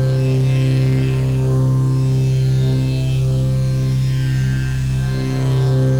Index of /musicradar/dystopian-drone-samples/Non Tempo Loops
DD_LoopDrone4-C.wav